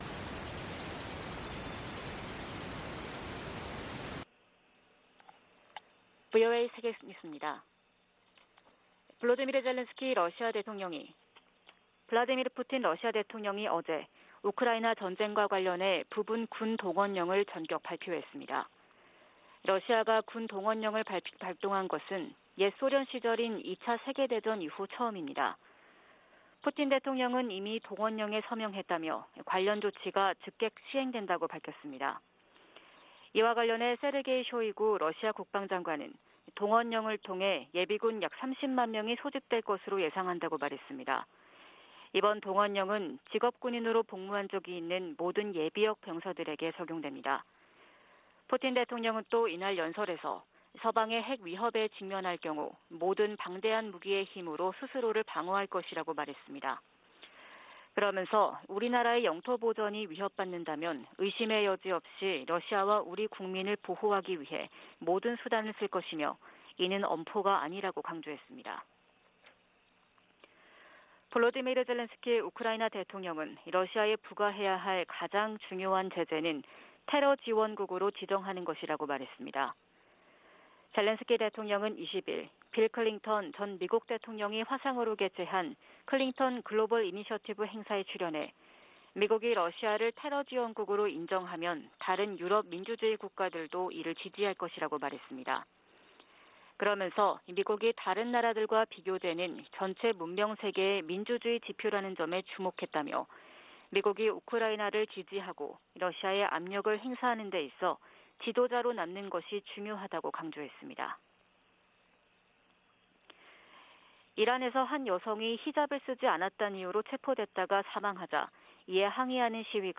VOA 한국어 '출발 뉴스 쇼', 2022년 9월 22일 방송입니다. 조 바이든 미국 대통령이 유엔총회 연설에서 유엔 안보리 개혁의 필요성을 강조할 것이라고 백악관이 밝혔습니다. 윤석열 한국 대통령은 유엔총회 연설에서 자유를 지켜야 한다고 역설했습니다. 미국 정부가 북한인권특사 인선을 조만간 발표할 것으로 기대한다고 성 김 대북특별대표가 말했습니다.